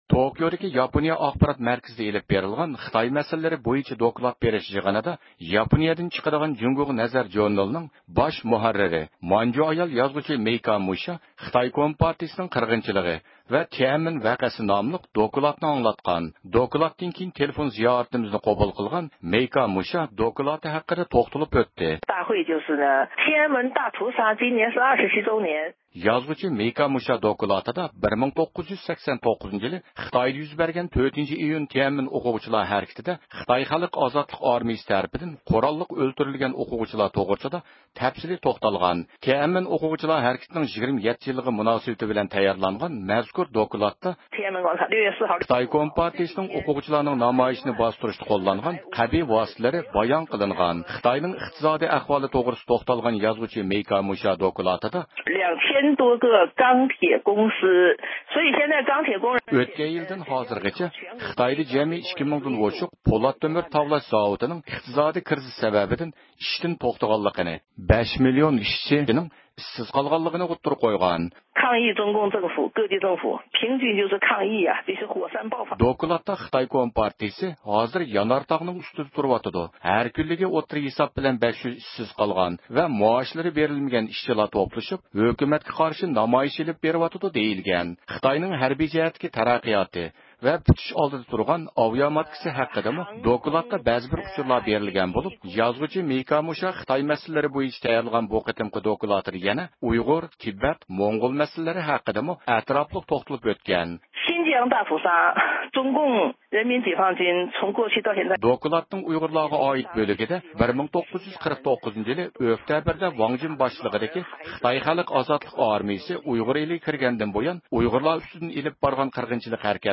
تېلېفون زىيارىتىمىزنى قوبۇل قىلغان